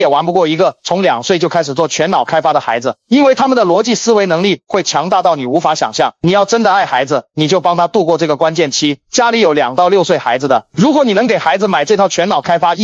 Profesjonalny edukacyjny głos profesora AI
Doświadcz wyrafinowanego, autorytatywnego głosu AI zaprojektowanego do wykładów akademickich, modułów e-learningowych i profesjonalnego edukacyjnego storytellingu.
Tekst na mowę
Narracja akademicka
Autorytatywny wokal